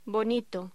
Locución: Bonito